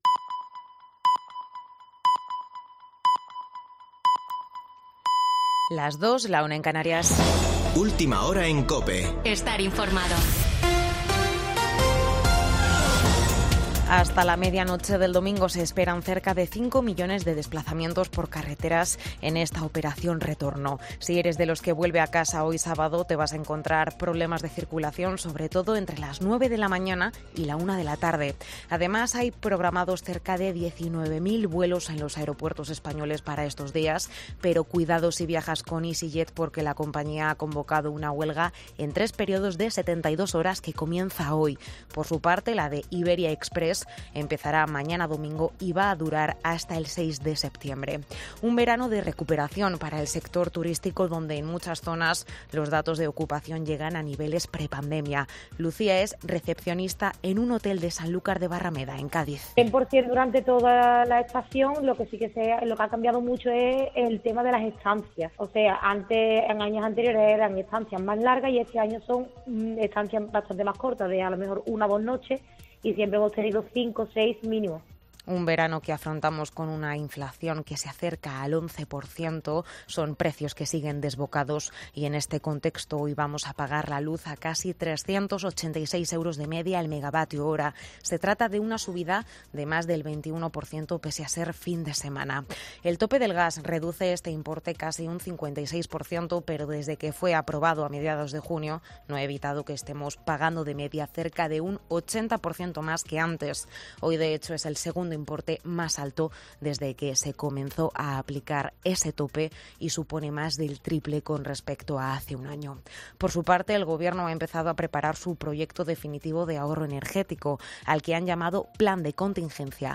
Boletín de noticias de COPE del 27 de agosto de 2022 a las 02.00 horas